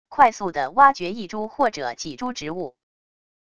快速的挖掘一株或者几株植物wav音频